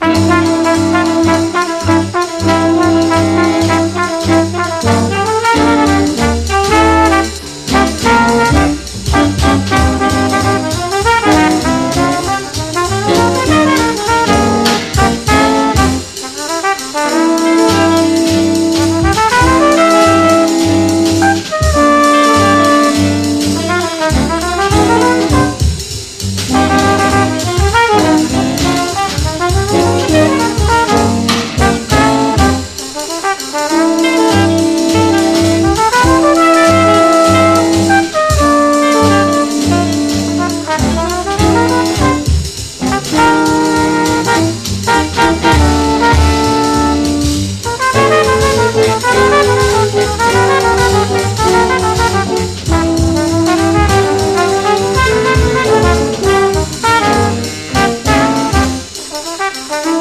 ROCK / S.S.W./A.O.R. / 70'S (US)
ソフト・サイケなまどろみハーモニー・ポップS.S.W.！